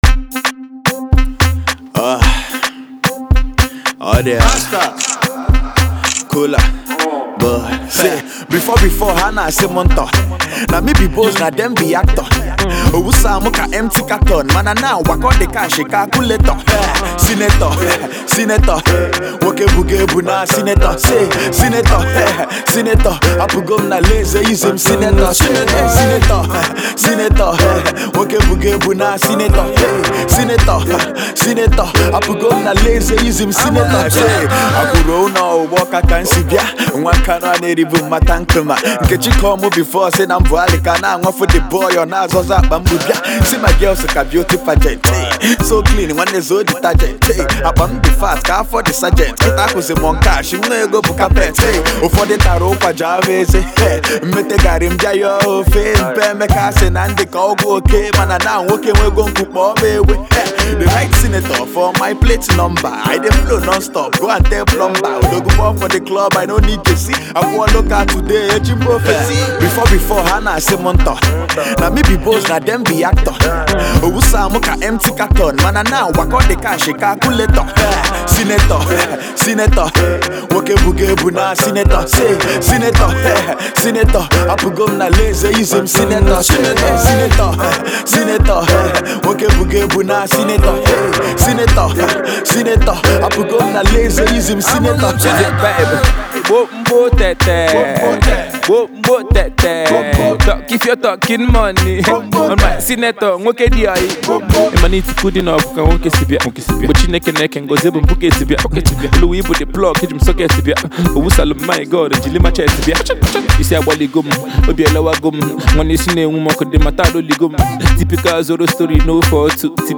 a club banger